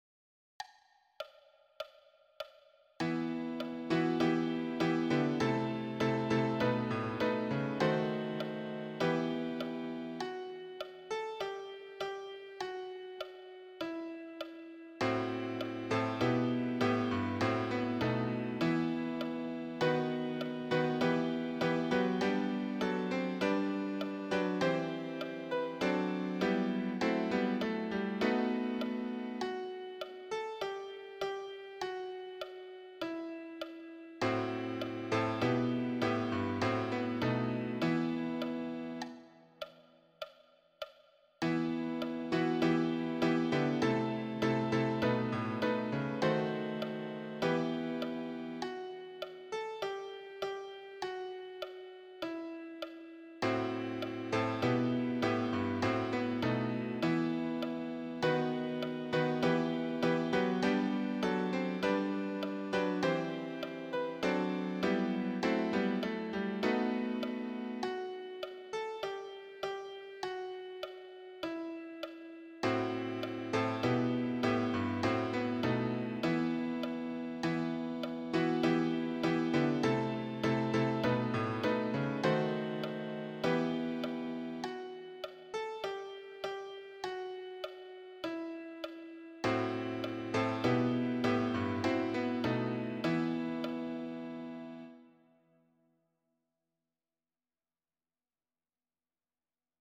Répétition SATB par voix
MESSE SAINT GERMAIN_AGNUS DEI_tutti.mp3